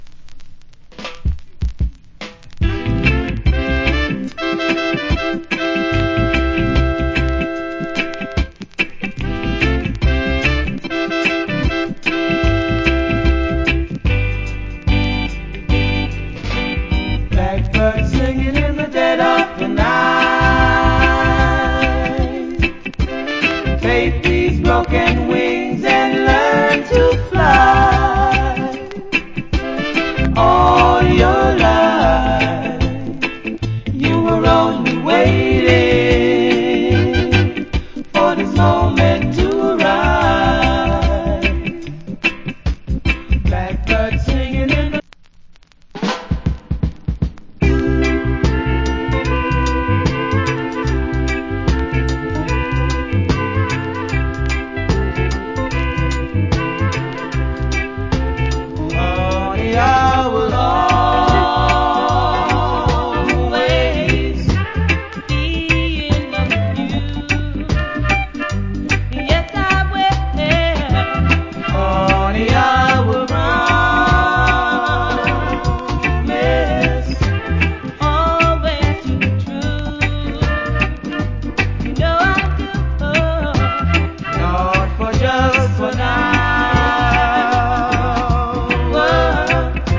Reggae Vocal.